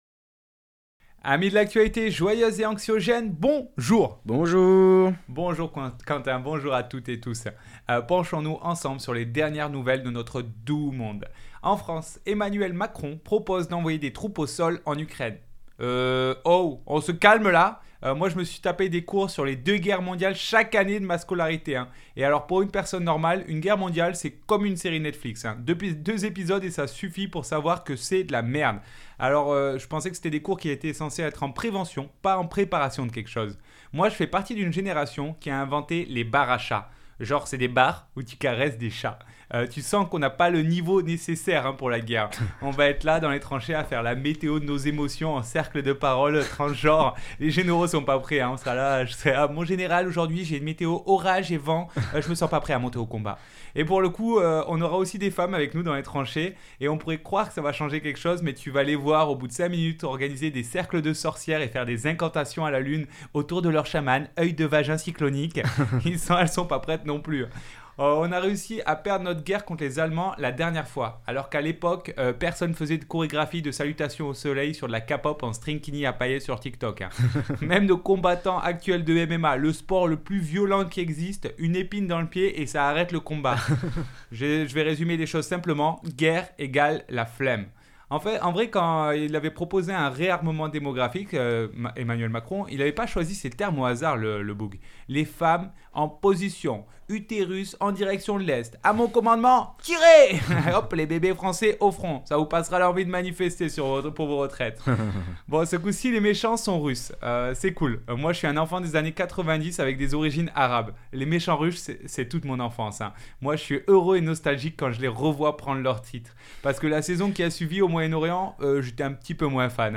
chronique humoristique n°17